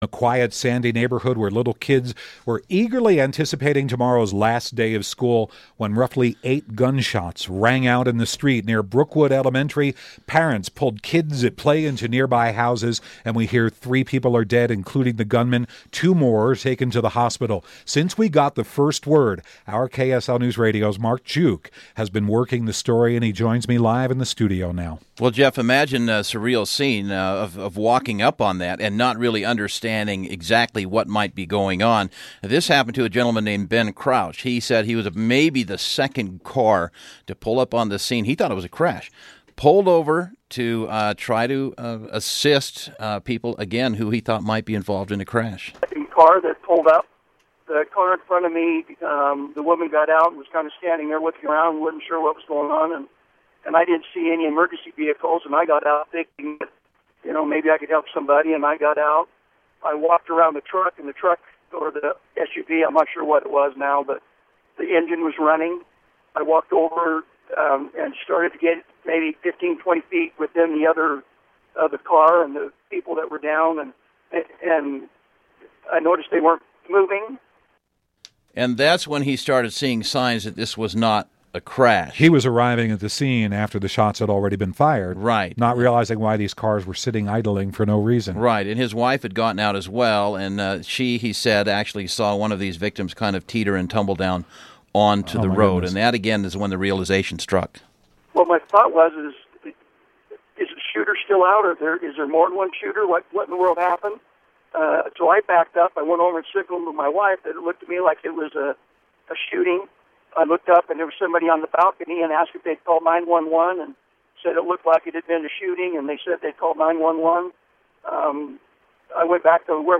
Witness recounts what he saw right after shooting in Sandy